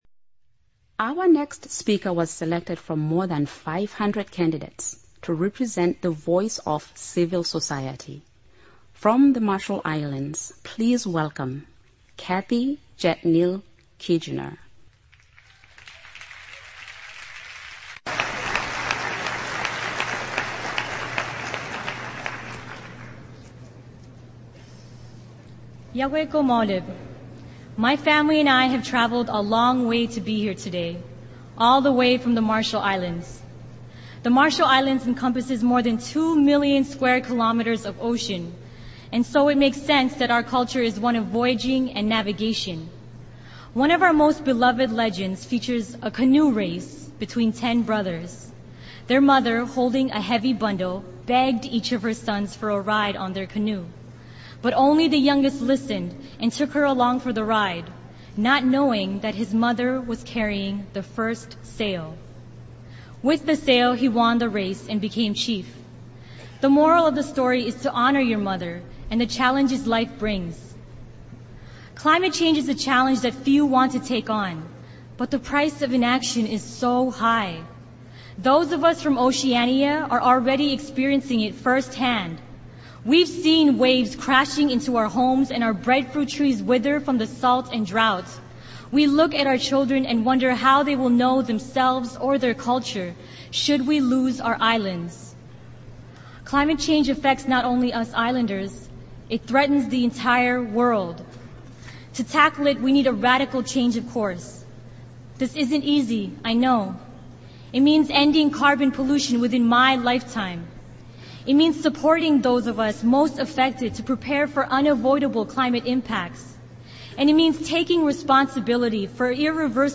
§Presentation and poetry reading at UN Climate Summit.
§Sound track only